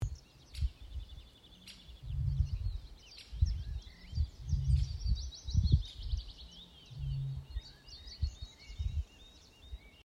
выпь, Botaurus stellaris
Administratīvā teritorijaAuces novads
СтатусПоёт
ПримечанияViens tuvāk dzied (ierakstā) un otrs kaut kur krietni talāk citā ezera malā